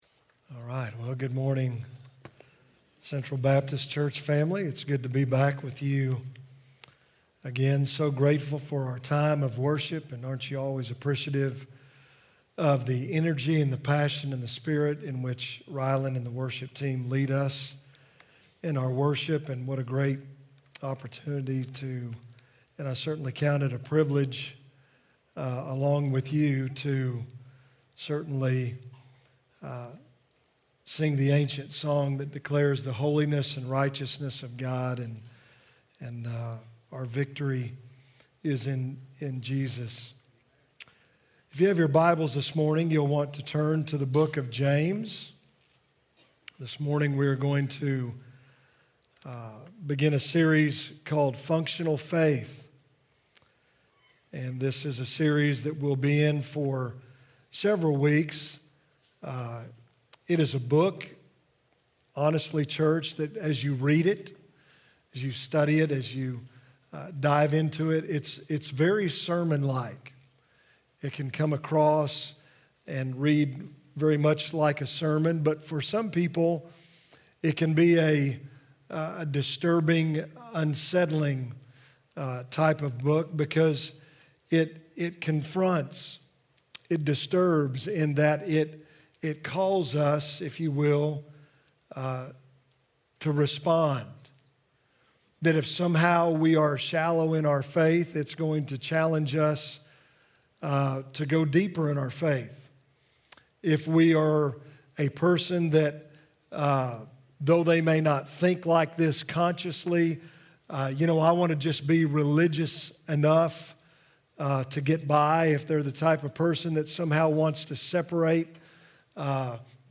A message from the series "Mother's Day."